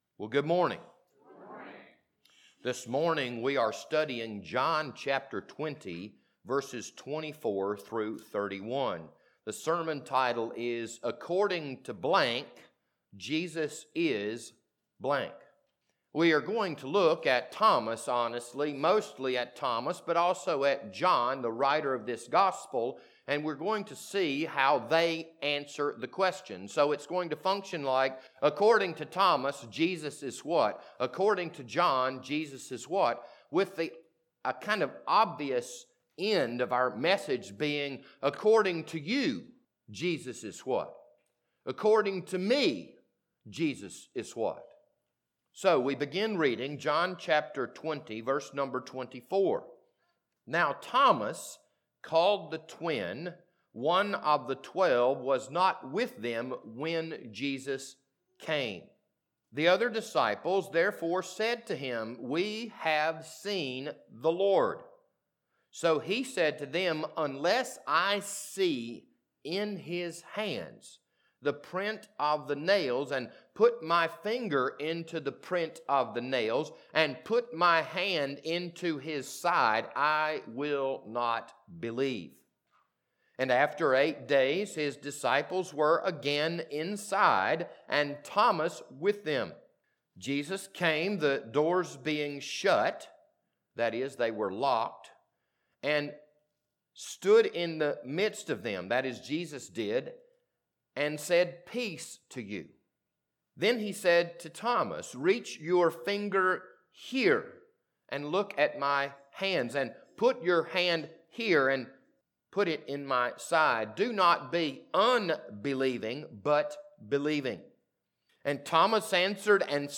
This Sunday morning sermon was recorded on September 19th, 2021.